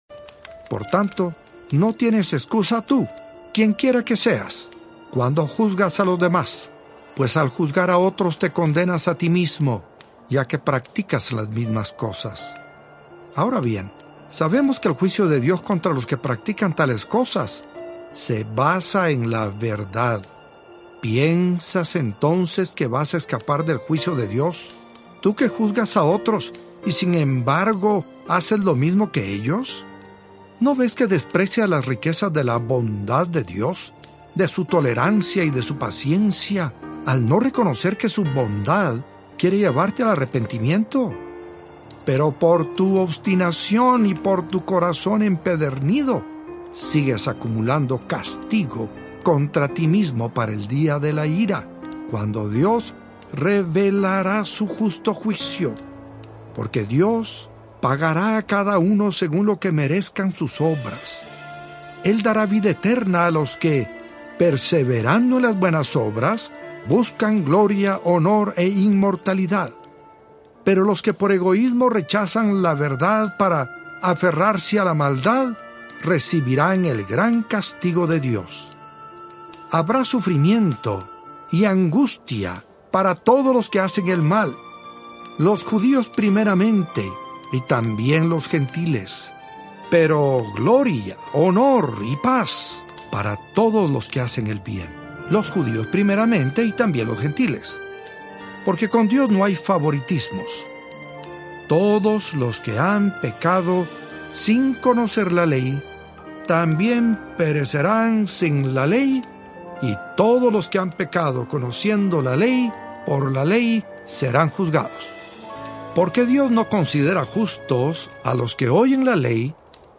Disfruta Romanos en las voces de siervos, adoradores, pastores y maestros de la Biblia cuyos ministerios y acciones han bendecido a la Iglesia del Señor de habla hispana.
1.3 Hrs. – Unabridged